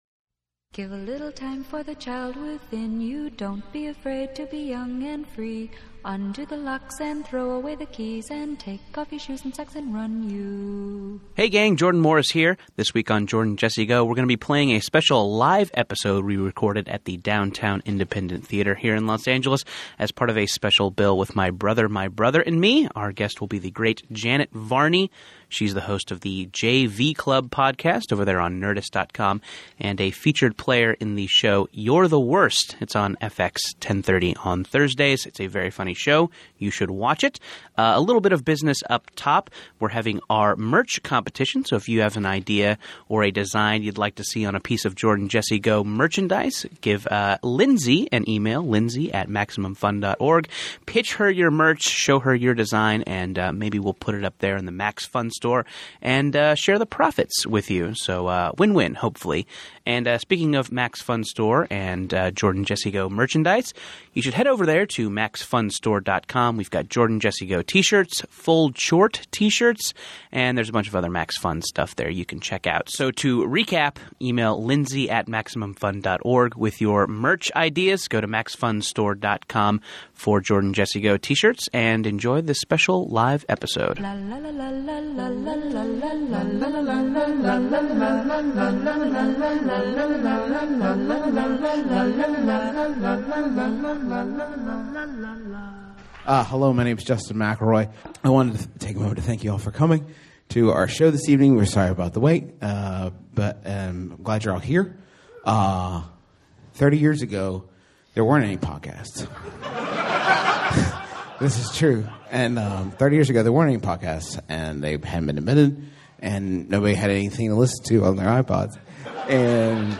Ep. 335: Live at the Downtown Independent with Janet Varney
Society & Culture, Comedy, Tv & Film